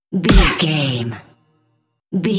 Electronic stab hit trailer
Sound Effects
Atonal
heavy
intense
dark
aggressive